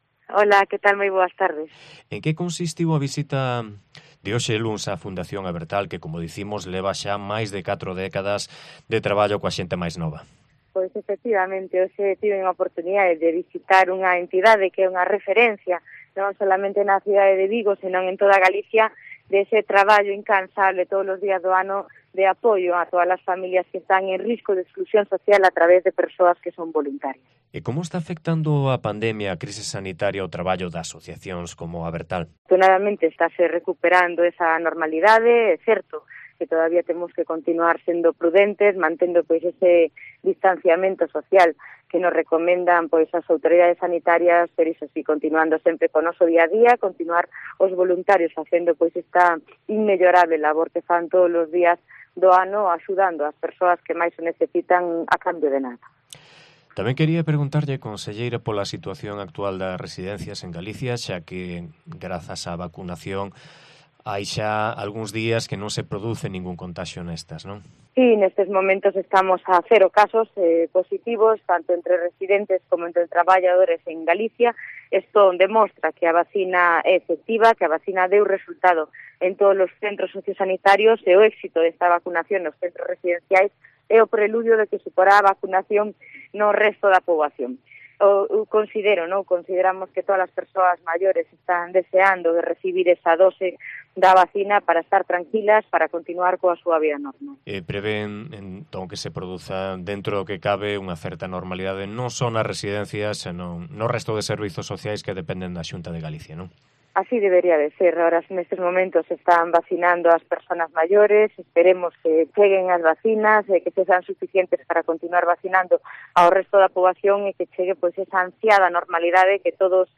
Entrevista a Fabiola García, conselleira de Política Social
AUDIO: Este mediodía en COPE Vigo hemos hablado con la conselleira de Política Social, Fabiola García, que ha estado hoy en Vigo para...